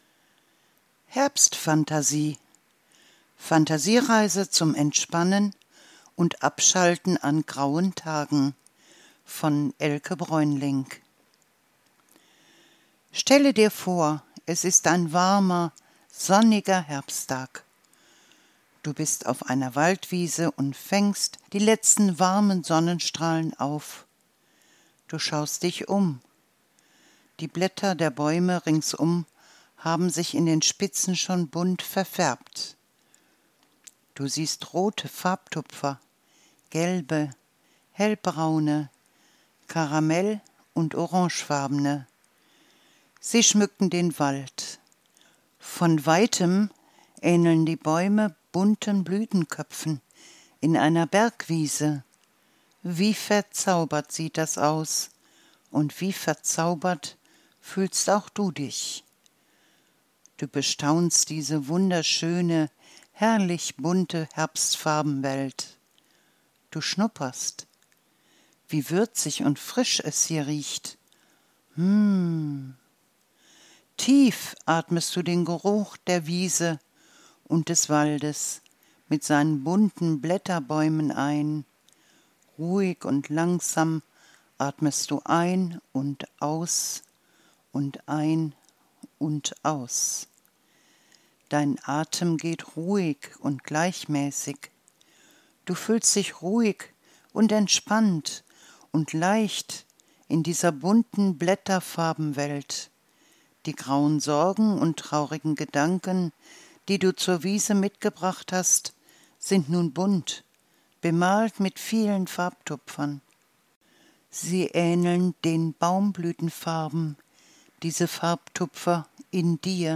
Fantasiereise für Kinder im Herbst zum Entspannen und Abschalten an „grauen“ Tagen